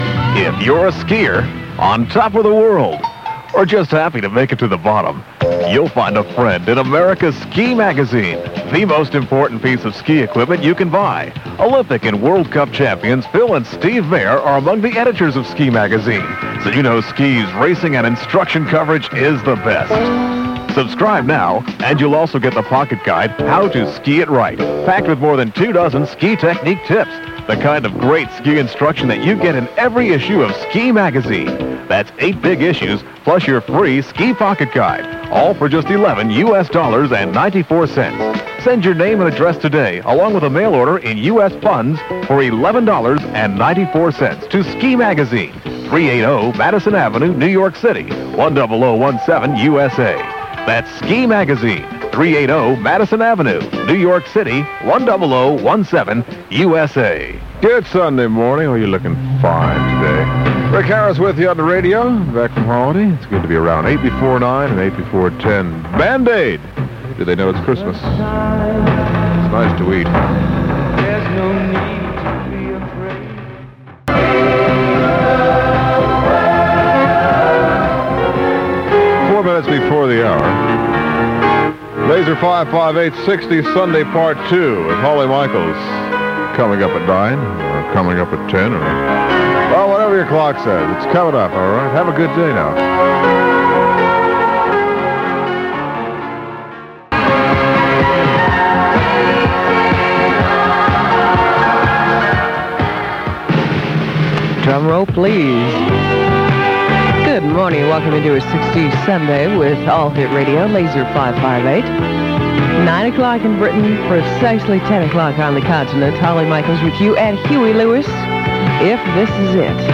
These were taped off-air on the Laser ship, the mv Communicator, on a cassette recorder.